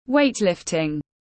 Môn cử tạ tiếng anh gọi là weightlifting, phiên âm tiếng anh đọc là /ˈweɪtlɪftɪŋ/
Weightlifting /ˈweɪtlɪftɪŋ/
Weightlifting.mp3